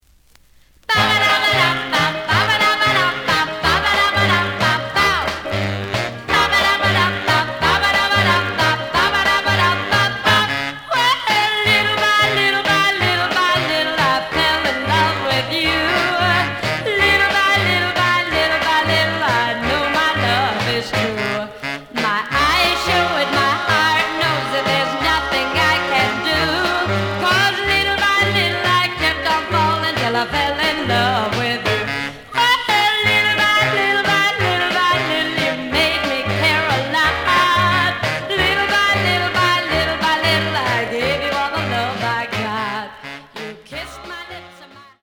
試聴は実際のレコードから録音しています。
●Genre: Rhythm And Blues / Rock 'n' Roll
EX-, VG+ → 傷、ノイズが多少あるが、おおむね良い。